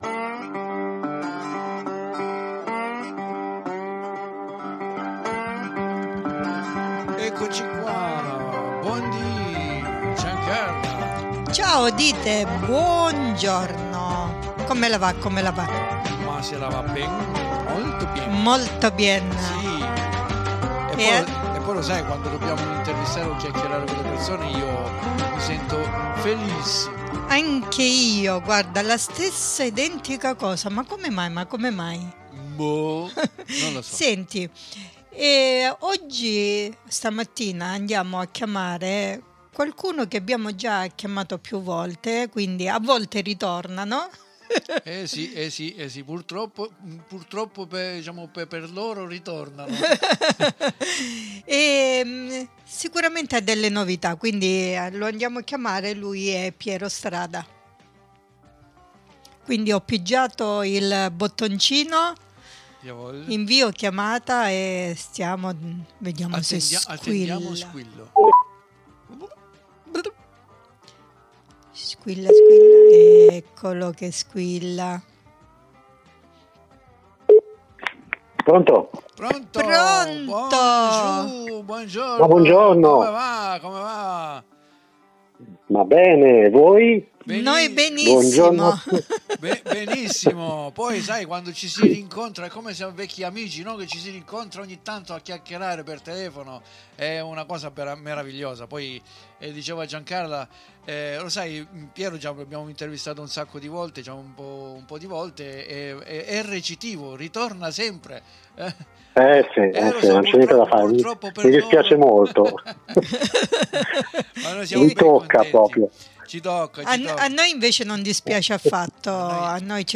SE ANCHE VOI SIETE CURIOSI, NON VI RESTA CHE SENTIRE LA SUA INTERVISTA , CONDIVISA QUI IN DESCRIZIONE .